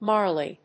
/ˈmɑrli(米国英語), ˈmɑ:rli:(英国英語)/